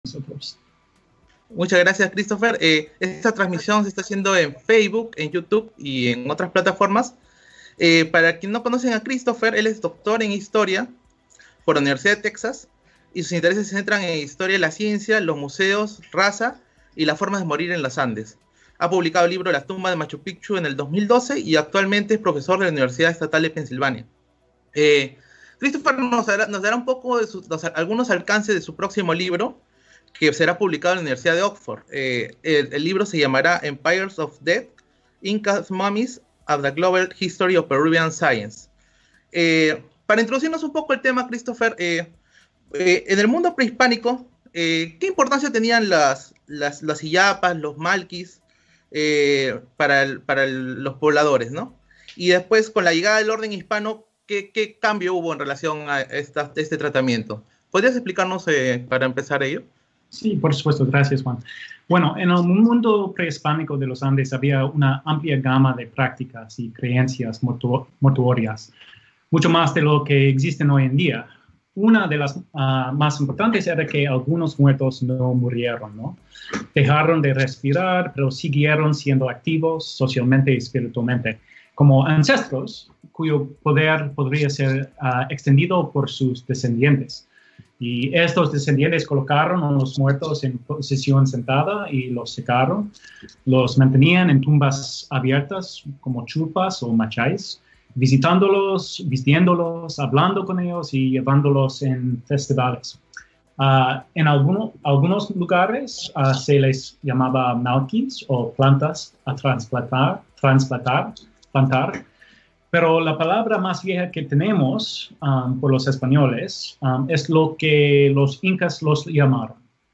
El paso de "Illapa" a momia es una degradación histórica se indica en este diálogo, por demás interesante.